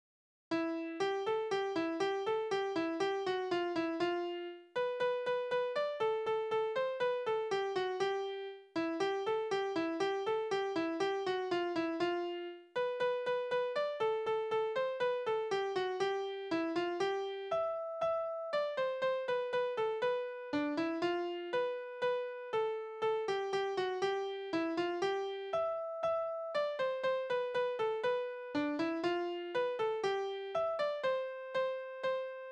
Spielverse:
Tonart: C-Dur
Taktart: 4/8
Tonumfang: große None
Besetzung: vokal